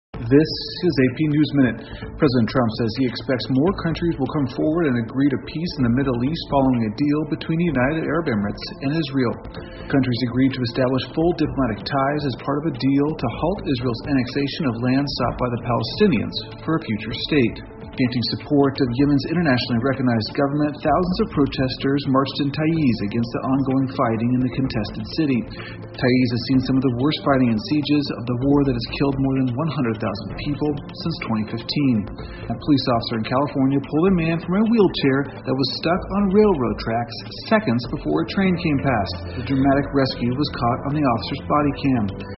美联社新闻一分钟 AP 以色列阿联酋建立全面外交关系 听力文件下载—在线英语听力室